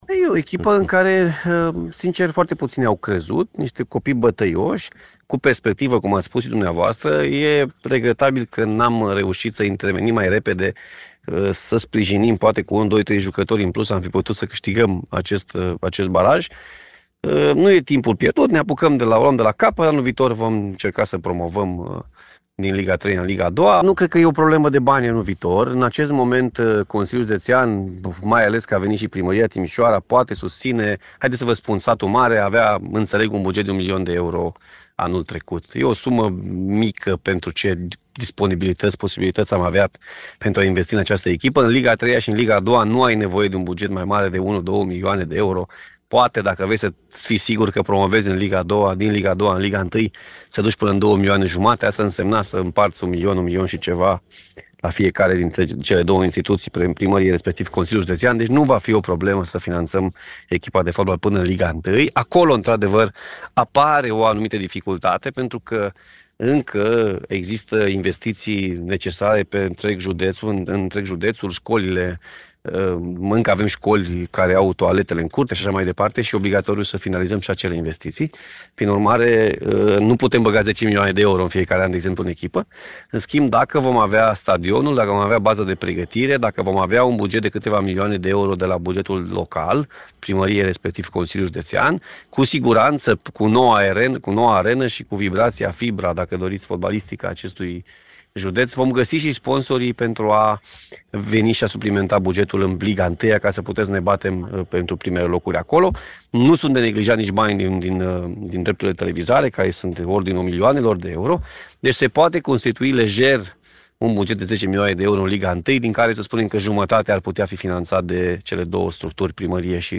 Președintele Consiliului Județean Timiș a fost invitat la ediția de sâmbătă a emisiunii Arena Radio.